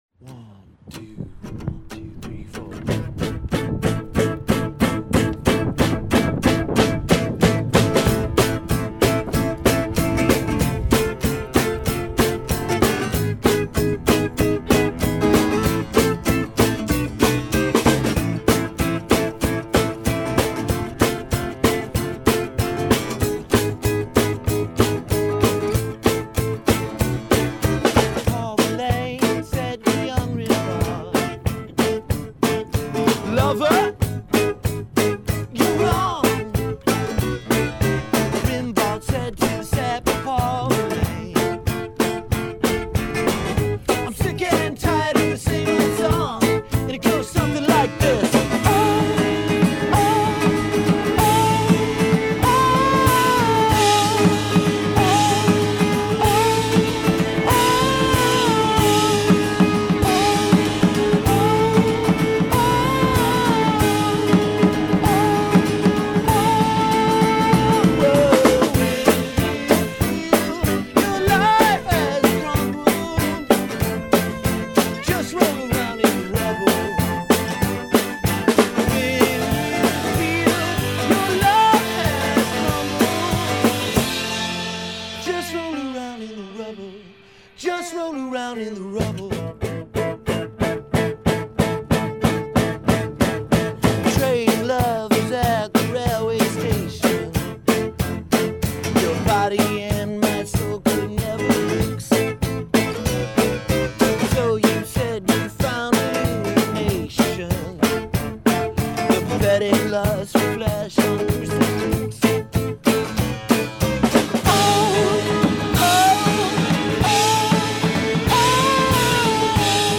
bittersweet chamber pop
a labor of love culminating in a roof-top performance